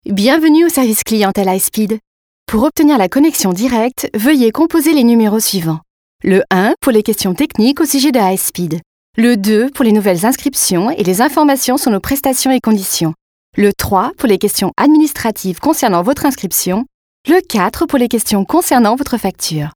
Sprecherin französisch. Stimmtyp: mittel / tief, warm, klar, freundlich, überzeugend.
Kein Dialekt
Sprechprobe: Industrie (Muttersprache):
Professional french female voice over artist